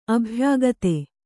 ♪ abhyāgate